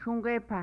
pronounced) — "Dog Head" a famous warrior and chief, he quickly became legendary, as we see from semi-mythological accounts of his life in the stories Great Walker's Medicine, The Warbundle Maker, and The Shawnee Prophet — What He Told the Hocągara.
Šų̄gépà.mp3